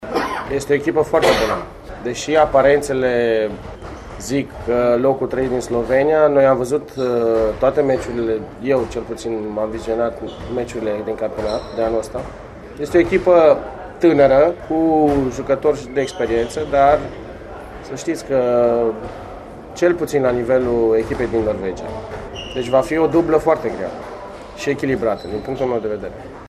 Cu prilejul conferinţei de presă dinaintea plecării